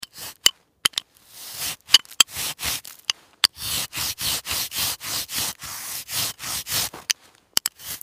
I love the ice shaving sound effects free download